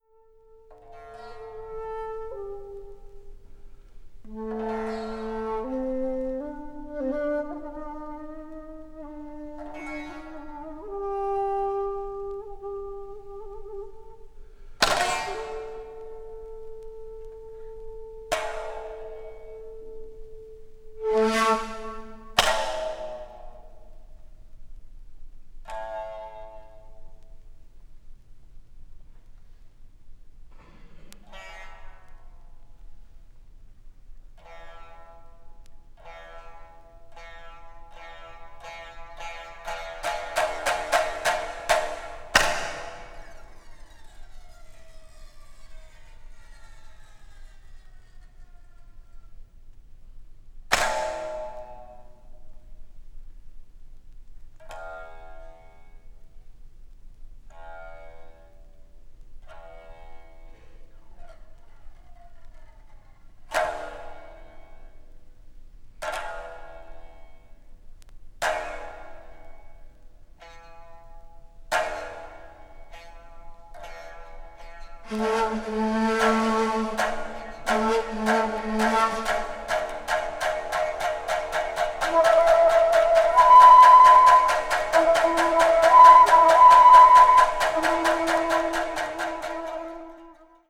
media : EX/EX,EX/EX(わずかにチリノイズが入る箇所あり)